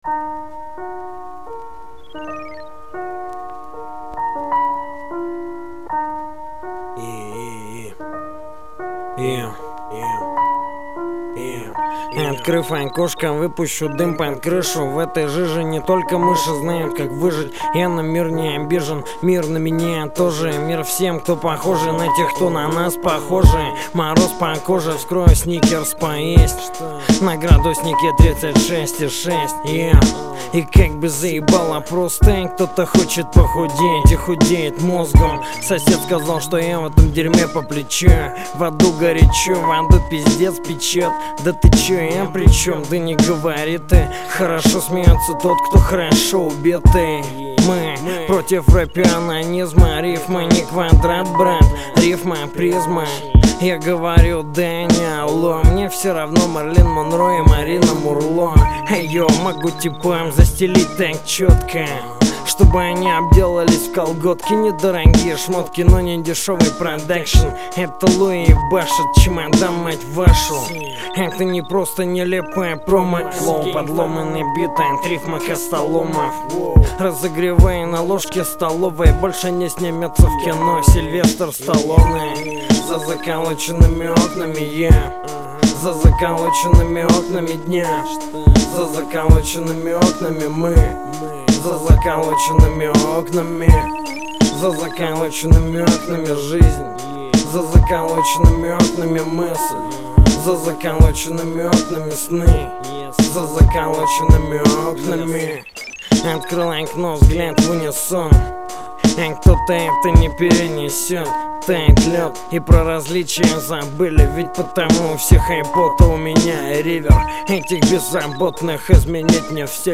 Тема: рэп